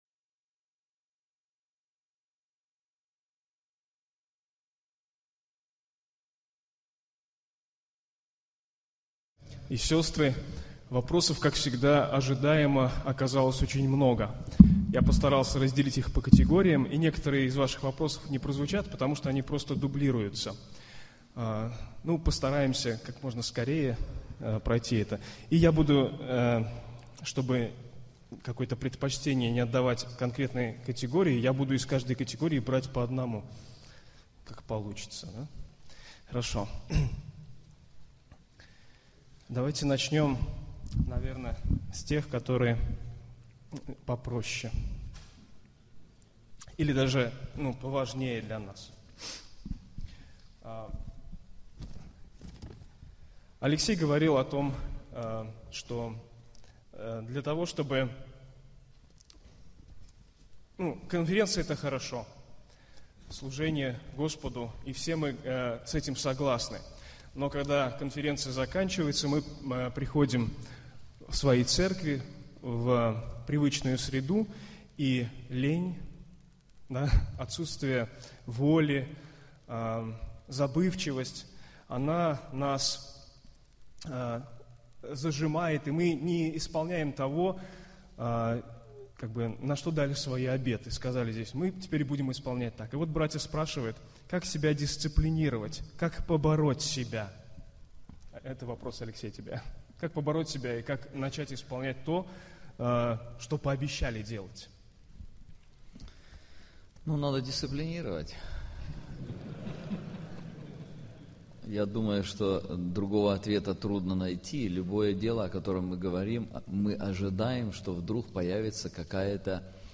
Вопросы и ответы (часть 1)